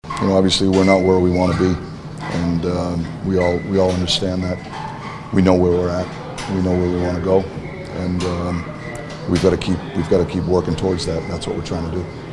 Coach Mike Sullivan reacted to the Pens missing the playoffs for a third straight year.